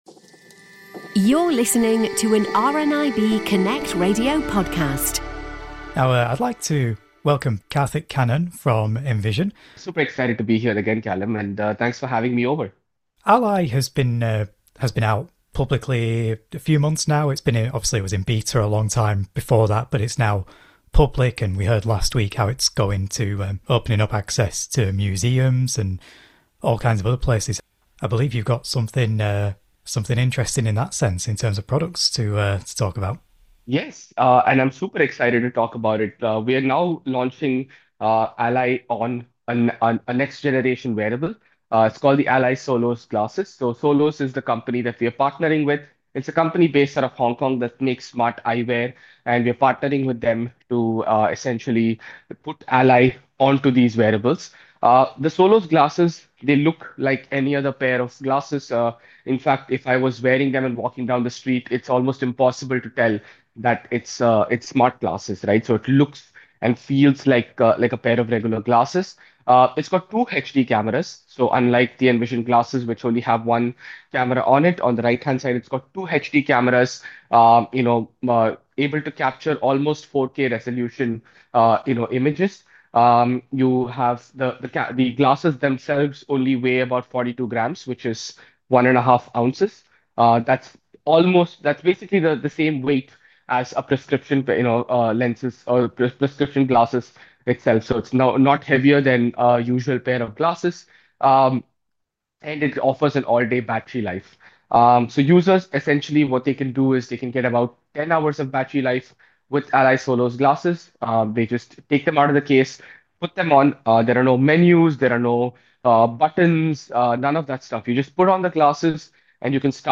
spoke to the team behind the wearable, hands-free AI glasses.